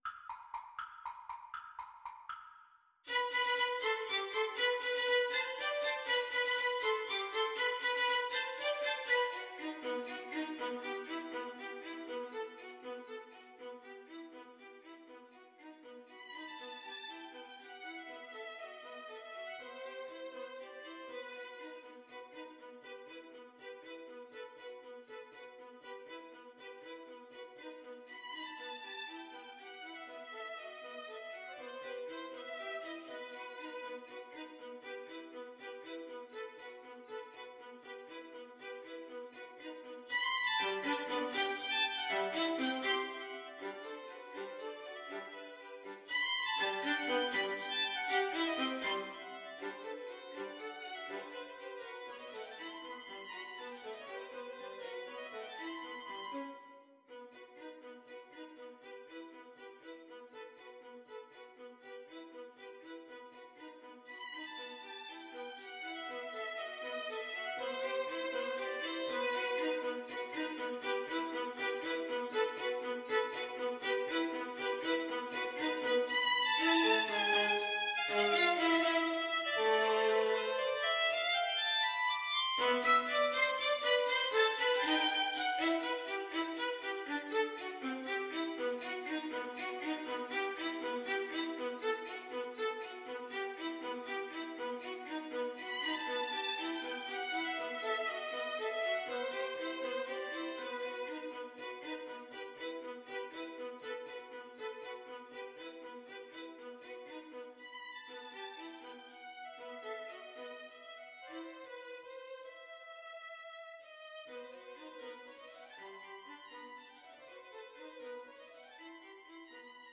Allegro vivo (.=80) (View more music marked Allegro)
Violin Trio  (View more Advanced Violin Trio Music)
Classical (View more Classical Violin Trio Music)